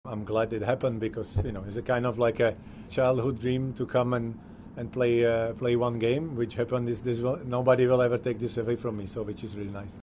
Petr Cech speaks about his childhood dream